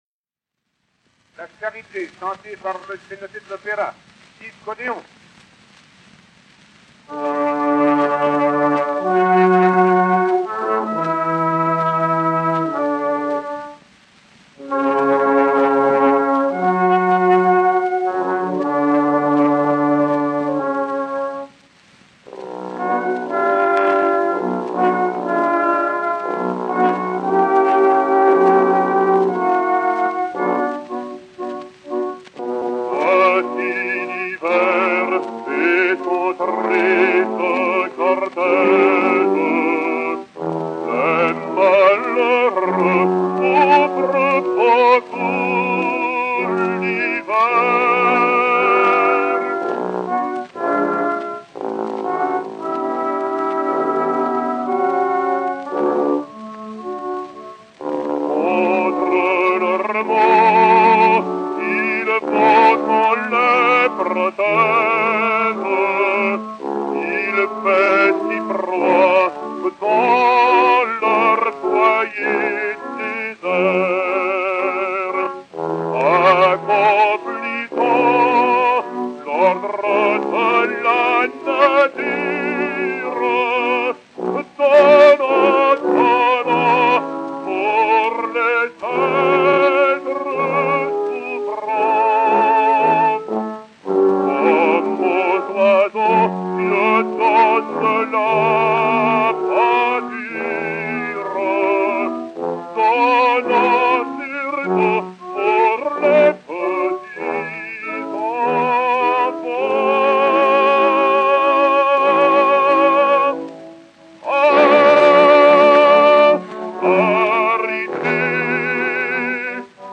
Hymne
Orchestre
XP 1182, enr. à Paris vers 1904